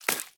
Sfx_creature_babypenguin_hop_08.ogg